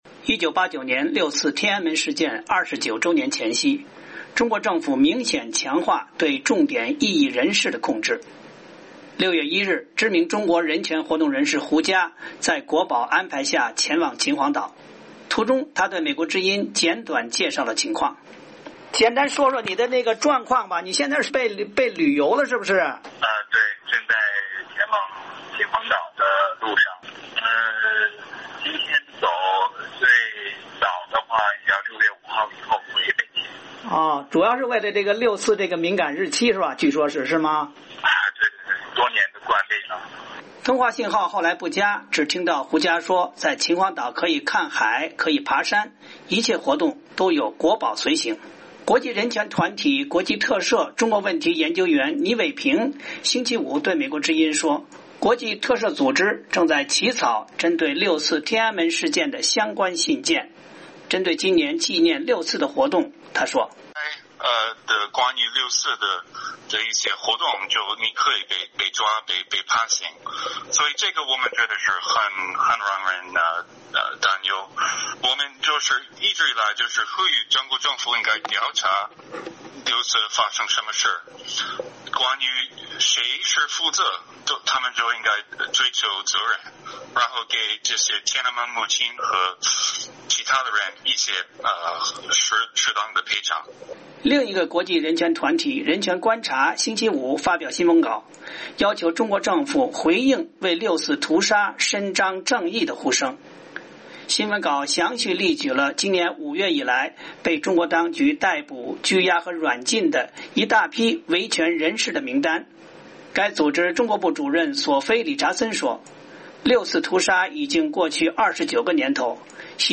6月1日,知名中国人权活动人士胡佳，由国保带领前往秦皇岛“旅游”，途中他对美国之音简短介绍了情况。
通话信号后来不佳，只听到胡佳说，在秦皇岛可以看海，可以爬山，一切活动都有国保随行。